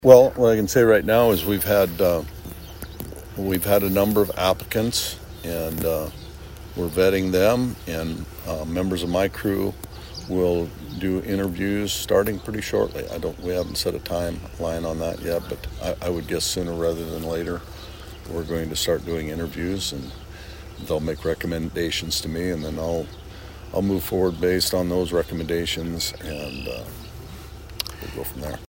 Rhoden talks about the end goal of this task force.